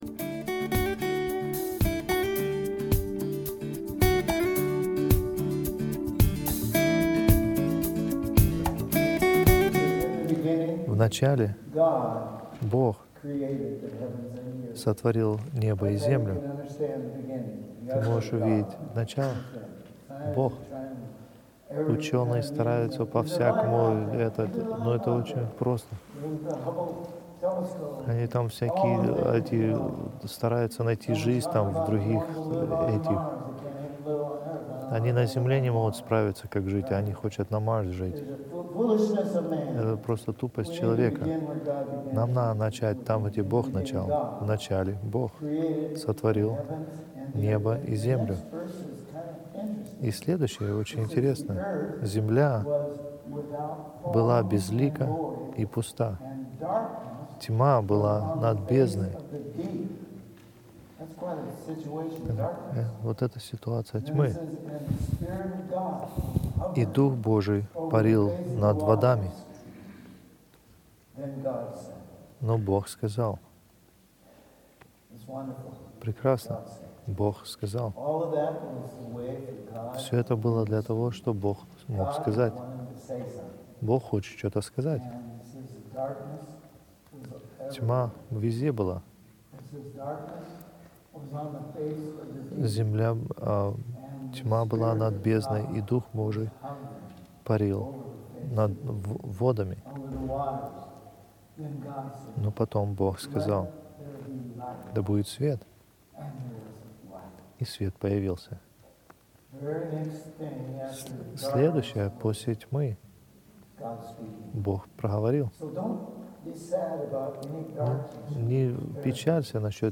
Русские проповеди